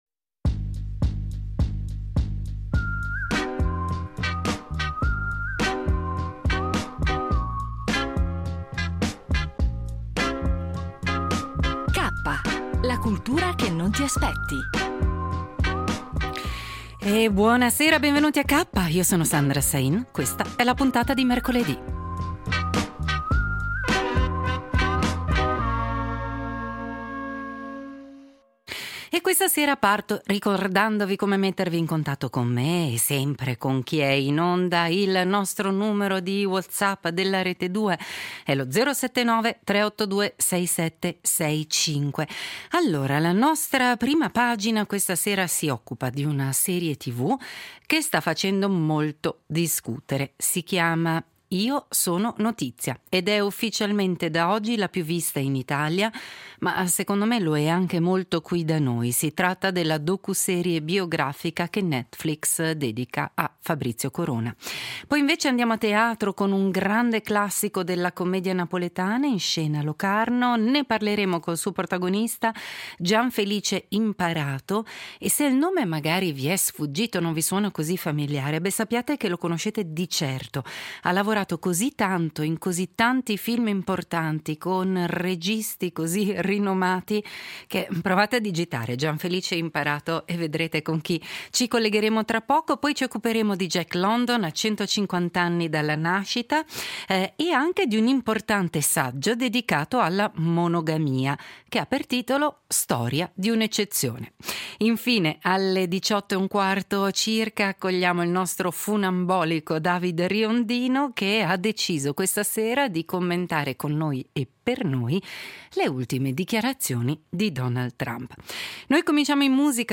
Spazio poi al teatro napoletano con Il medico dei pazzi di Eduardo Scarpetta e l’intervista a Gianfelice Imparato.